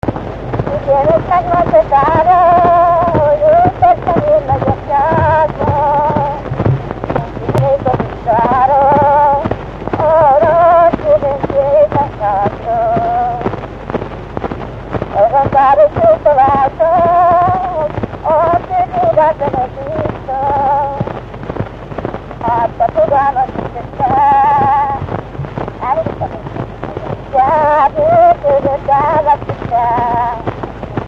Erdély - Udvarhely vm. - Máréfalva
ének
Stílus: 3. Pszalmodizáló stílusú dallamok